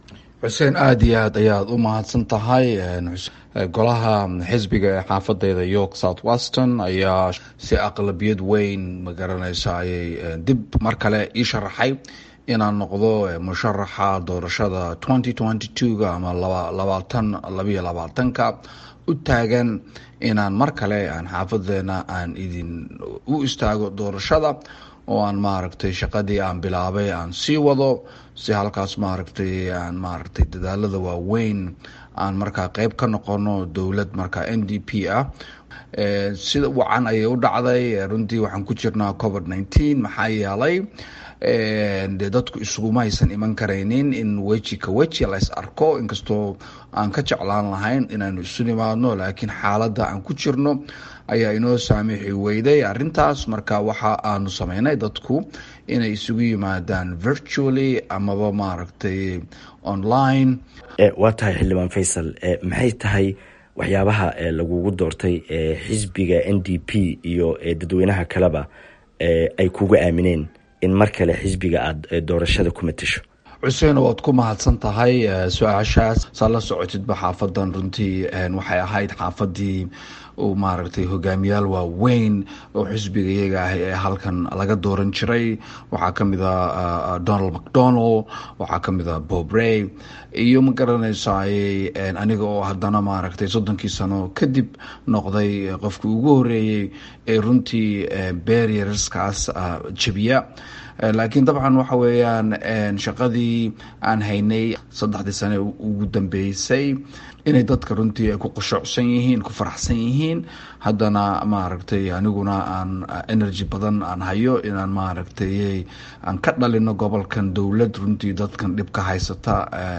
Xildhibaan Faysal oo la hadlay VOA ayaa sheegay in dib u doorashadiisa xisbiga ay muujineyso in xisbigaba iyo taageerayaashiisa ay la dhacsan yihiin shaqada uu hayey muddadii uu kursiga fadhiyey.